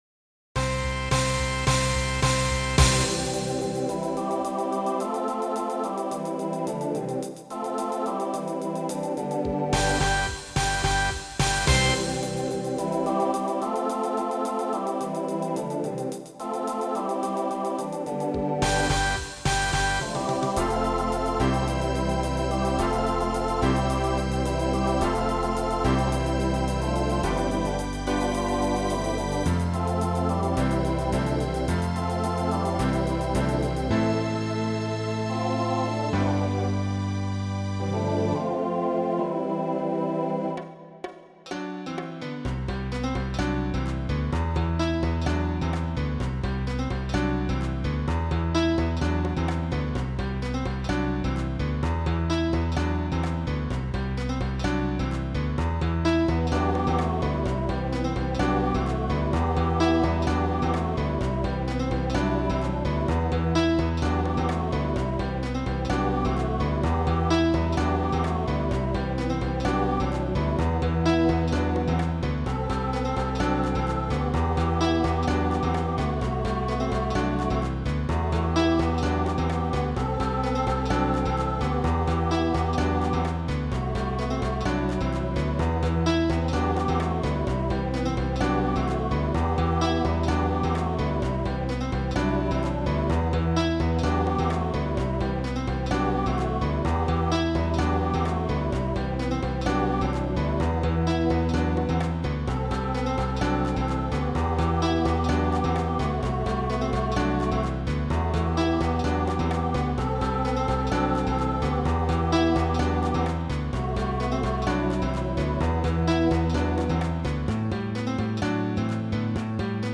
Voicing SATB Instrumental combo Genre Pop/Dance , Rock
Mid-tempo